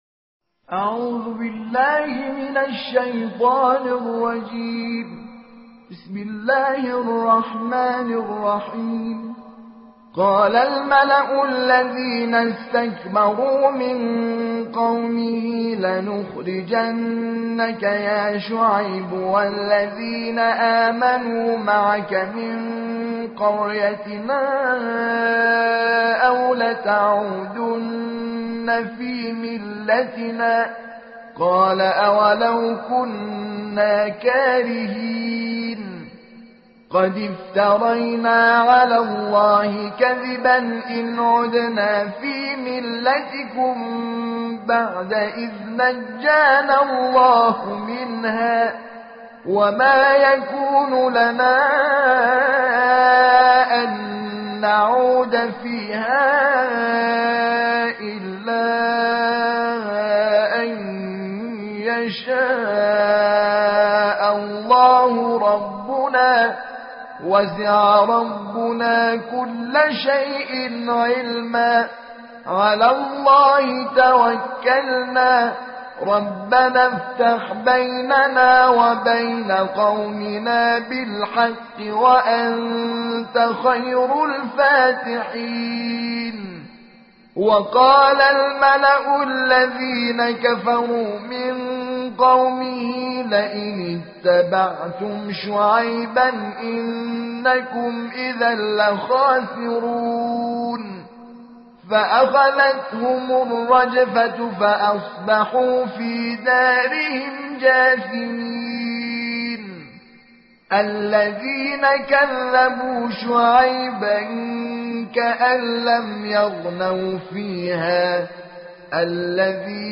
دانلود ترتیل قرآن کریم با قرائت استاد شاکرنژاد به تفکیک 30 جزء
shaker-nezhad.tartil-joz.09.mp3